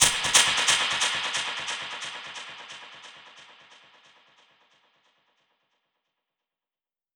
Index of /musicradar/dub-percussion-samples/134bpm
DPFX_PercHit_C_134-07.wav